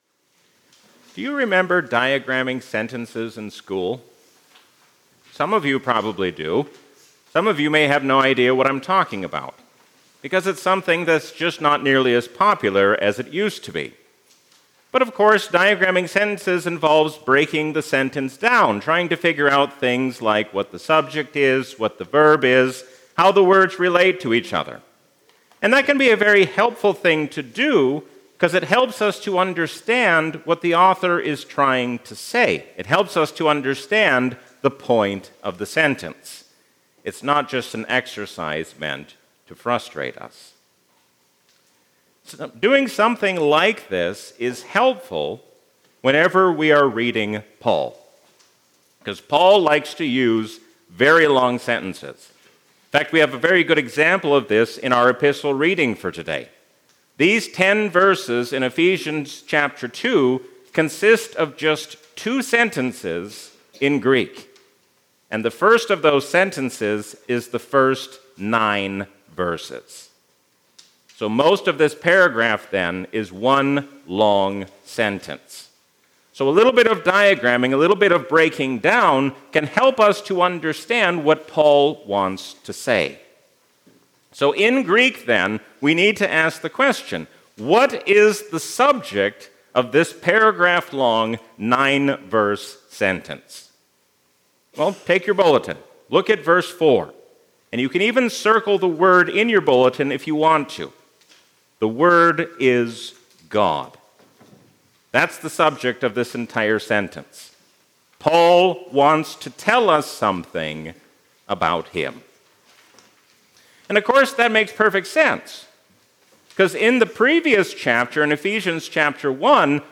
Sermon
A sermon from the season "Christmas 2021." Suffering as Christians shouldn't surprise us, because we enter into glory through suffering with Jesus.